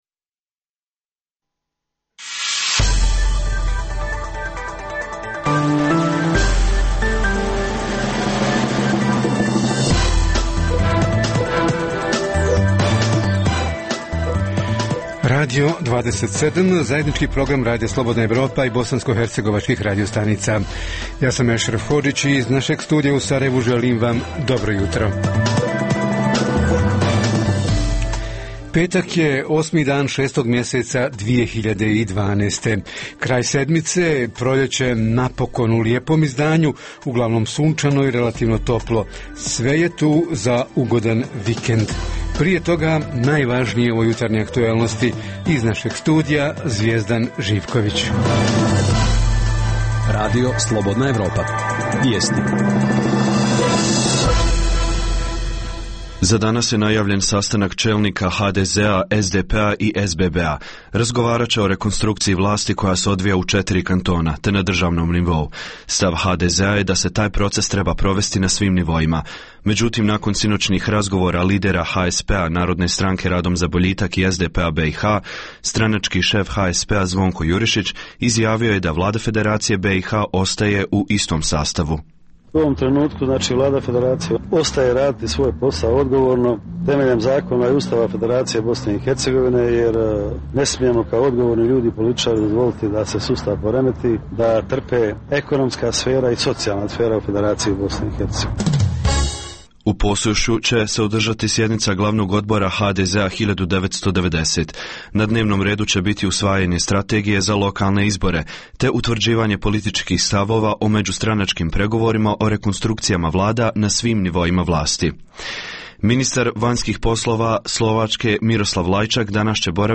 Proizvodnja, prikupljane i prerada ljekovitog i aromatičnog bilja – može li to u BiH biti unosan posao? Reporteri iz cijele BiH javljaju o najaktuelnijim događajima u njihovim sredinama.
Redovni sadržaji jutarnjeg programa za BiH su i vijesti i muzika.